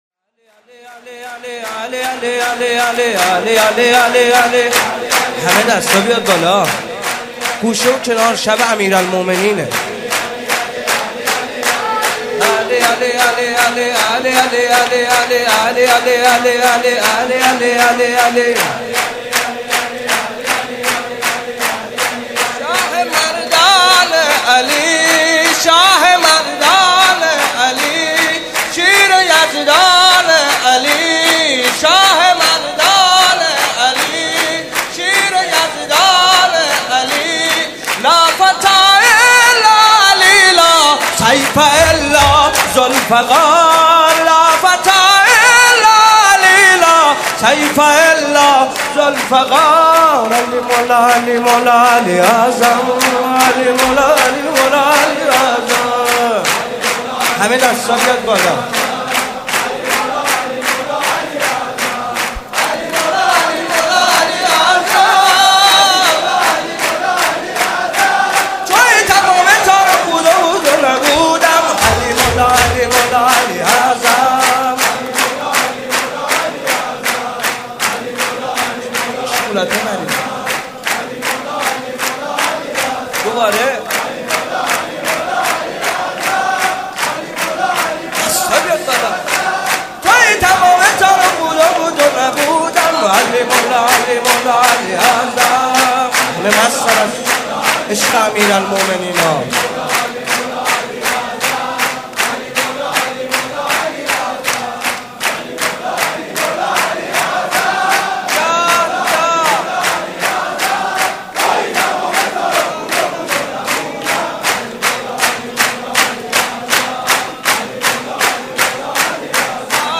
مناسبت : ولادت امیرالمومنین حضرت علی علیه‌السلام
قالب : سرود